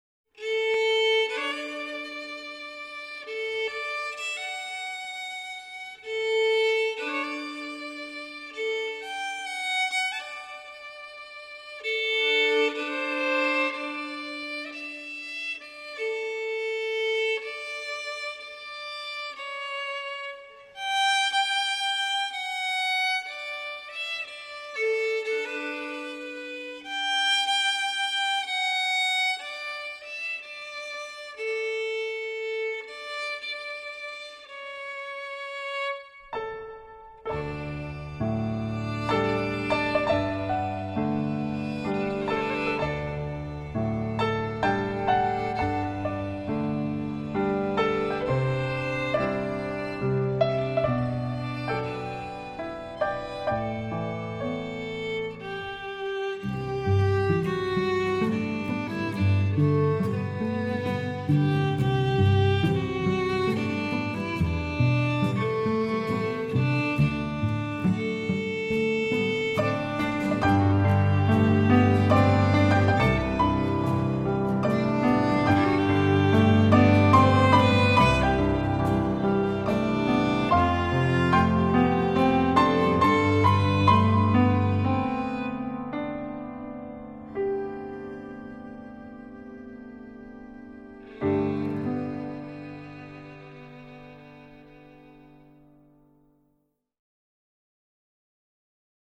violin, mandolin, cello, piano, acoustic guitar
fiddle and mandolin